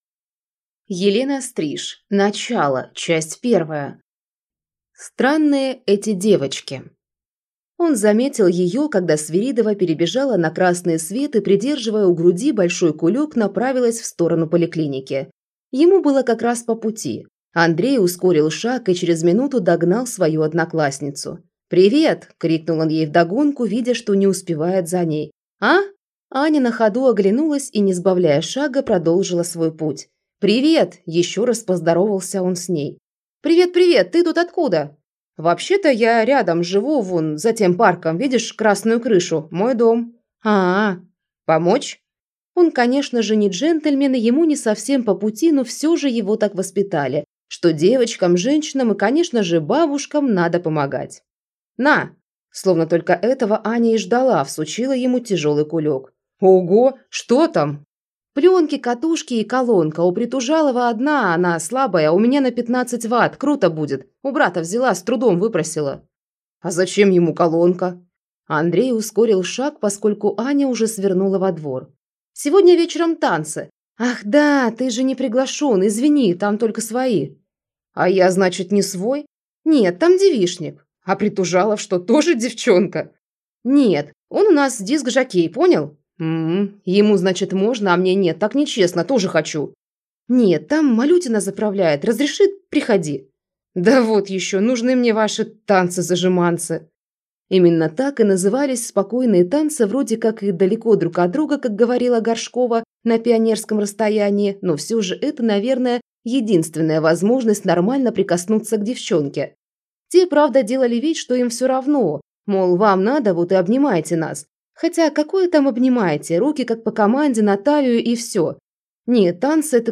Аудиокнига Начало. Часть 1 | Библиотека аудиокниг